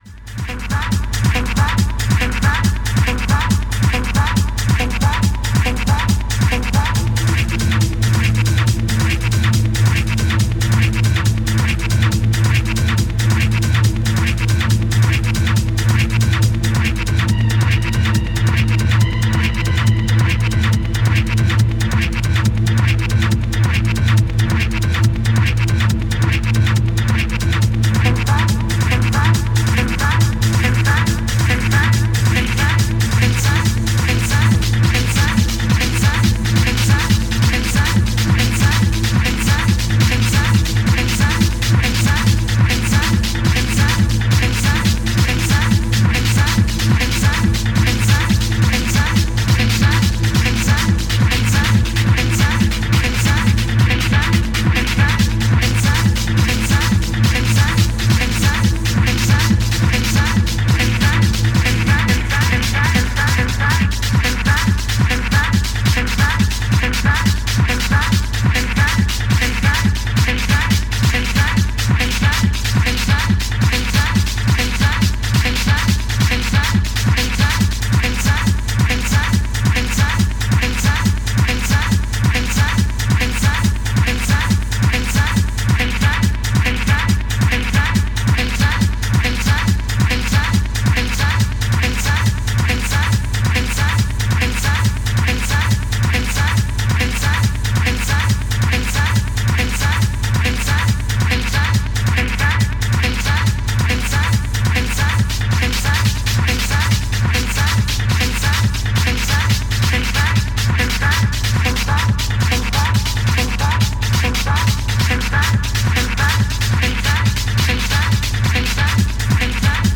STYLE Techno